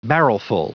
Prononciation du mot barrelful en anglais (fichier audio)
Prononciation du mot : barrelful